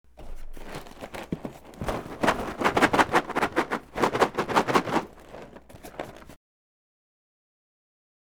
Cereal Box Shaking Sound
household
Cereal Box Shaking